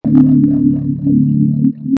Weird Alien Noise Sound Button - Free Download & Play